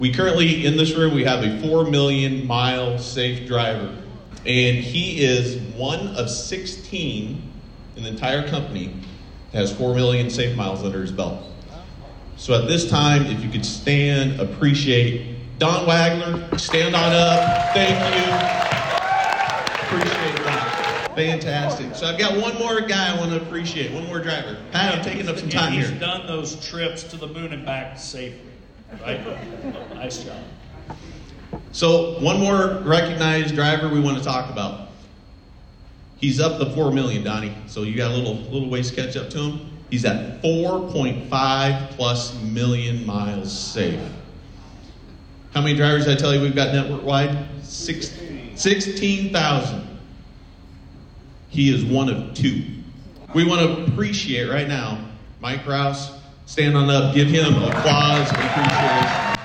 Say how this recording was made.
MOUNT PLEASANT, IA – April 30, 2025 — A crowd of employees, community leaders, and special guests gathered with smiles and applause Wednesday morning to celebrate a major milestone: the 40th anniversary of the Walmart Distribution Center in Mount Pleasant.